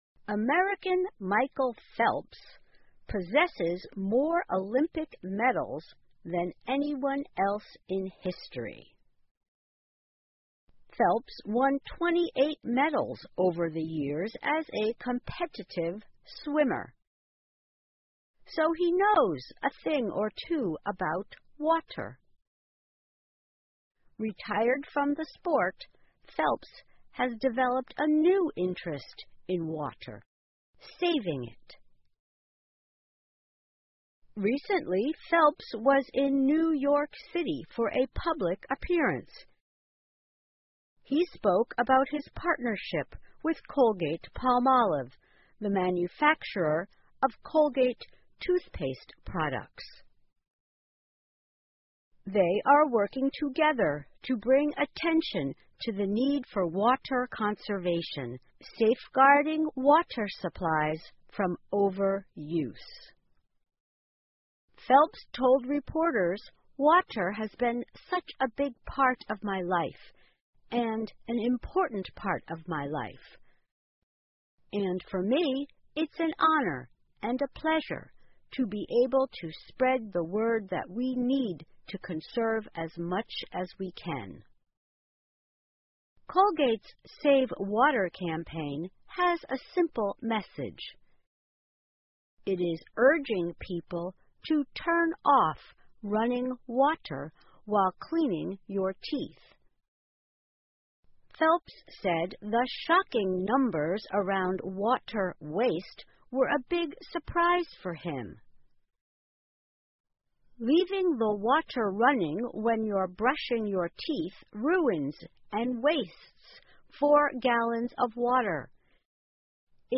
在线英语听力室游泳运动员米迦勒·菲尔普斯谈节约用水的听力文件下载,2018年慢速英语(四)月-在线英语听力室